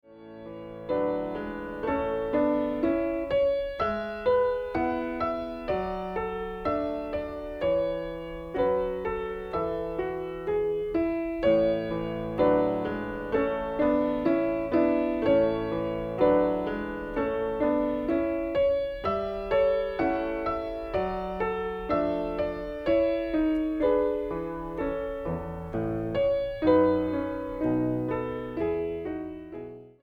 Piano/Organ Ensembles Piano Duets
Piano Duet